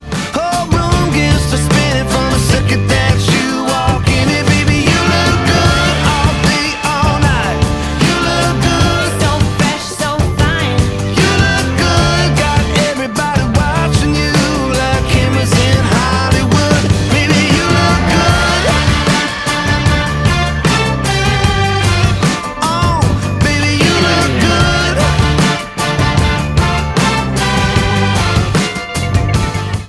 • Country
lead vocals